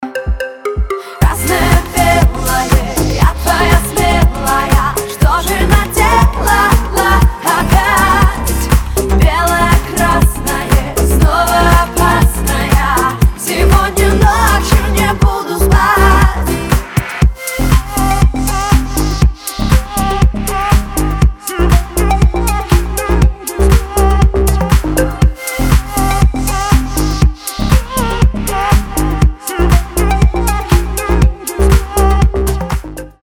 • Качество: 320, Stereo
поп
громкие
deep house
dance